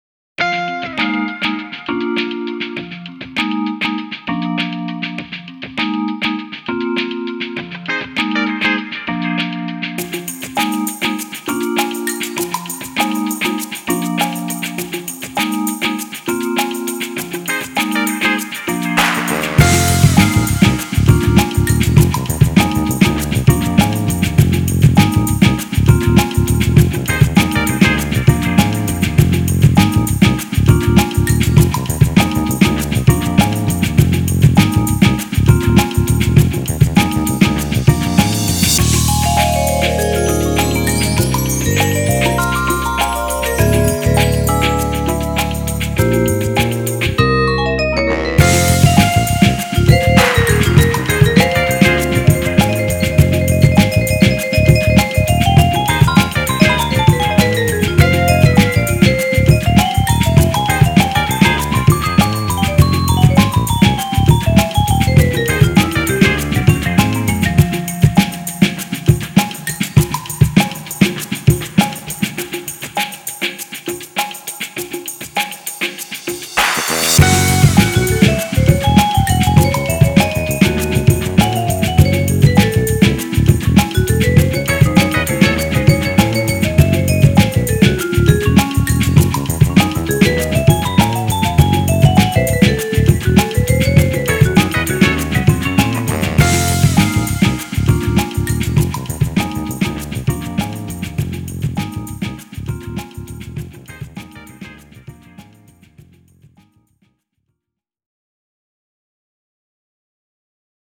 BPM100
soundtrack